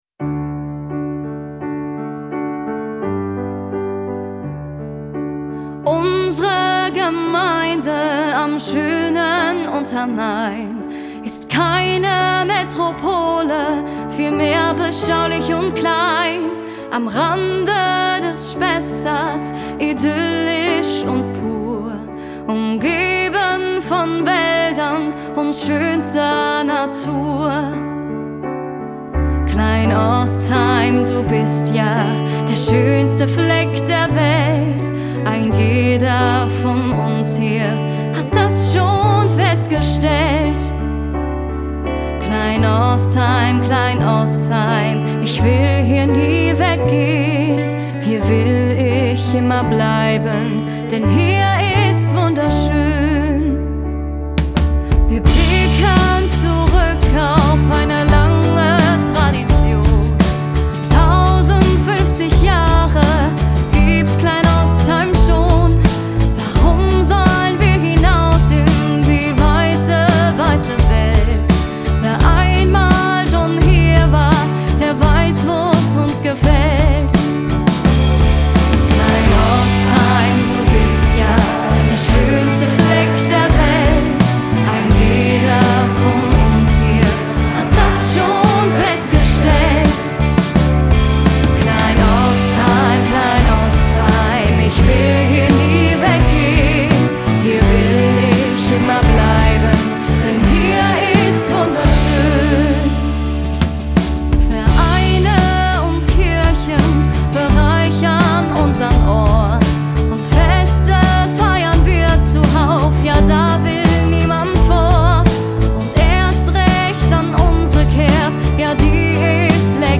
fanden wir eine unverwechselbare Stimme